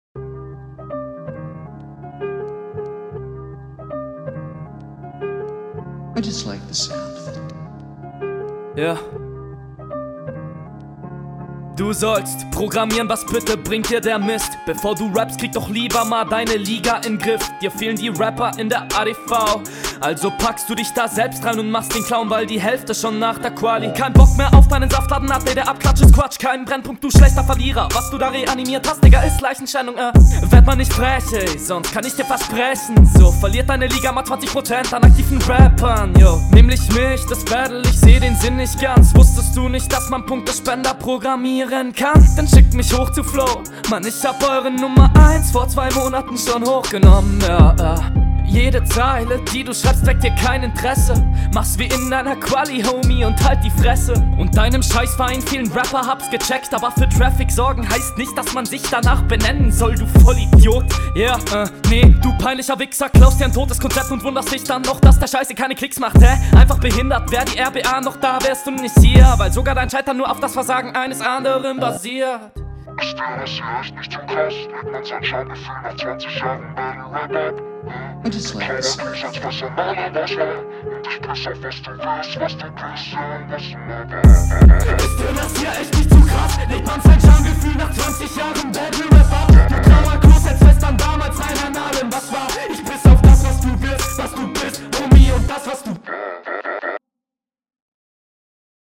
Intro atmosphärisch und auch biiiiiiissel realtalk.